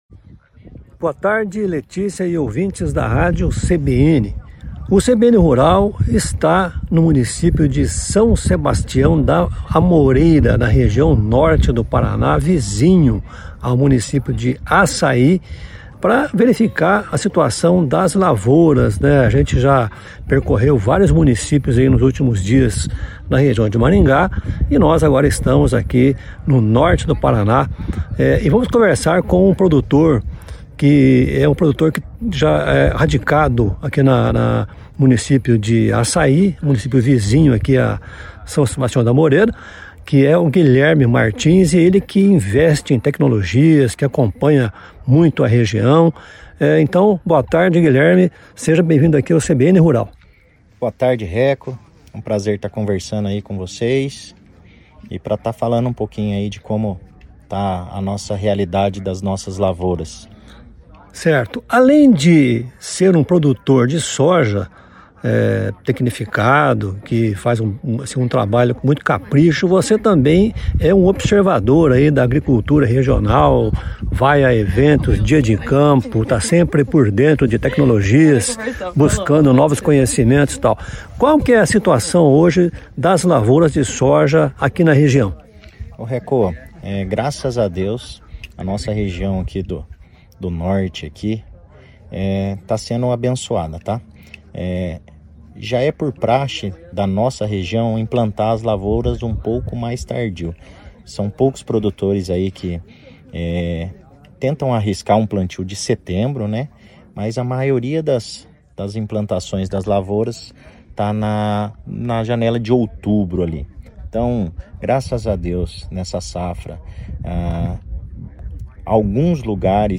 Em visita aos municípios de Assaí e São Sebastião da Amoreira, na região Norte do Paraná, o CBN Rural conversou com produtores de soja e a expectativa é de uma safra cheia.